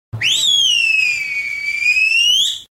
تسميات : message new funny small toys